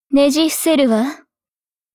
Cv-49906_battlewarcry.mp3